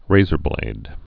(rāzər-blād)